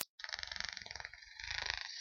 怪异的响声
描述：我在录制话筒的过程中做了这怪异的响声。
标签： 幽灵 缠扰行为 捕食者 奇怪 邪恶 咯咯声 怪异 阴森 可怕
声道单声道